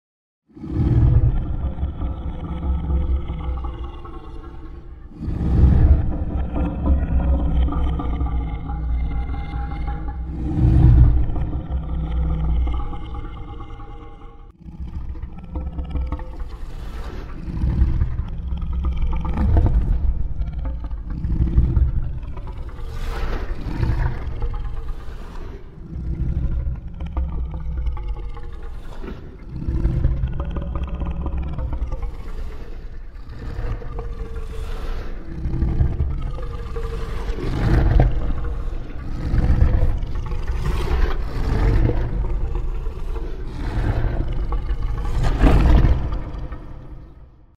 Звуки дракона